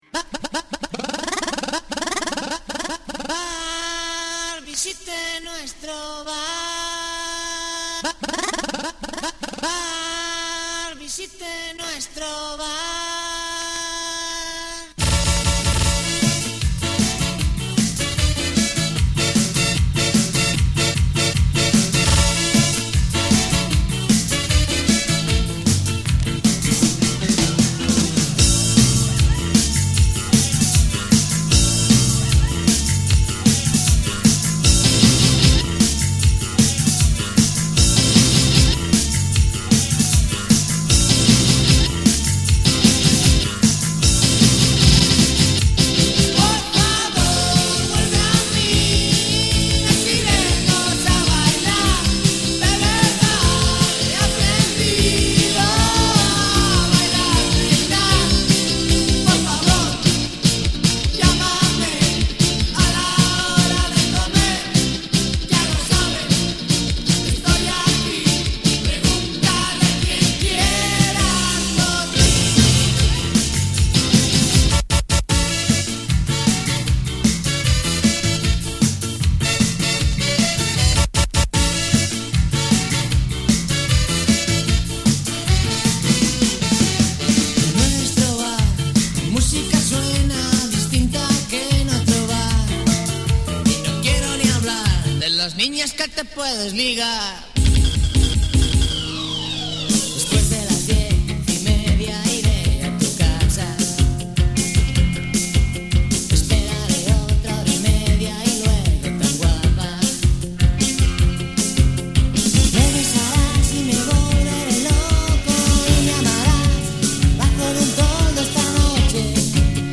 Rock En Espainol